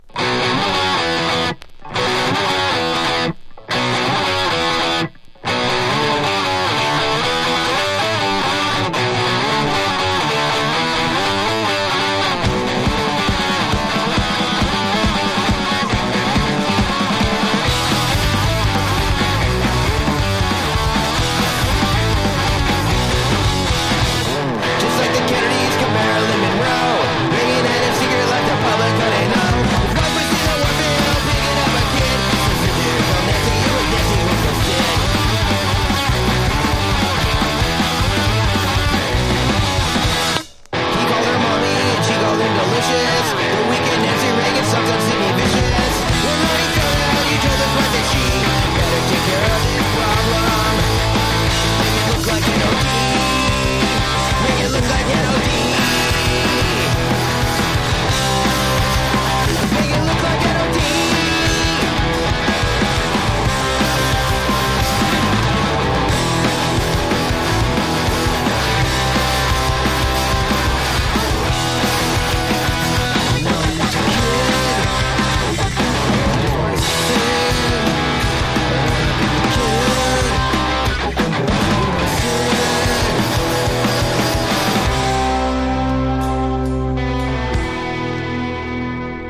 1. 90'S ROCK >
メロコア / スカパンク